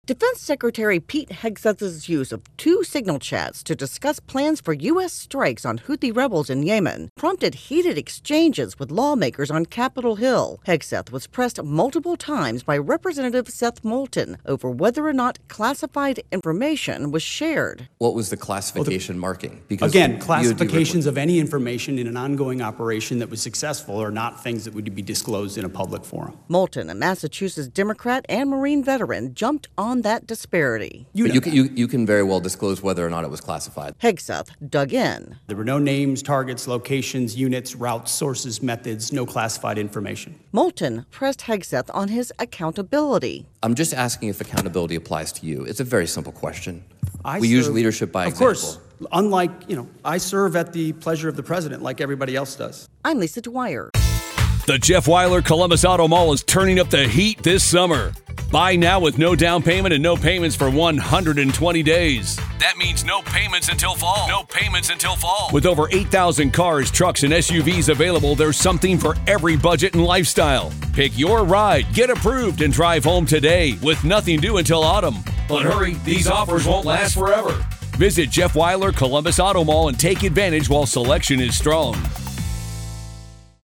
reports on a testy exchange on Capitol Hill over the use of a public app by the Defense Secretary.